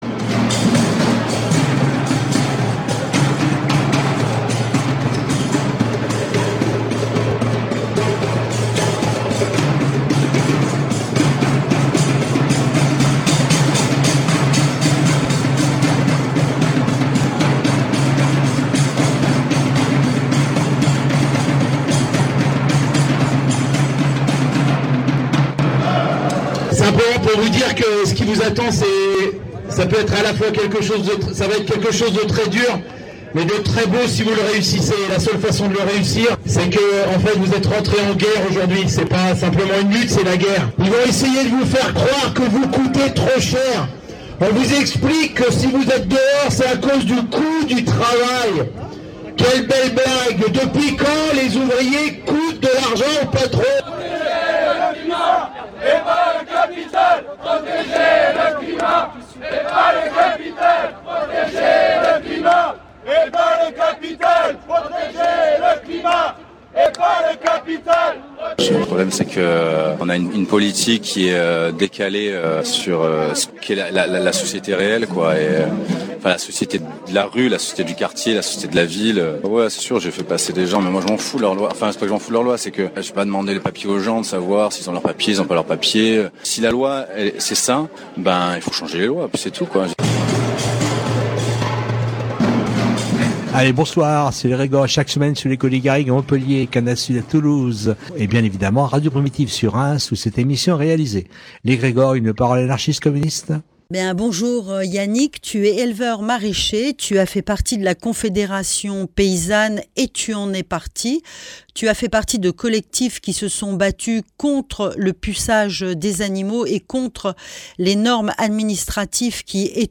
Dans cette émission, un entretien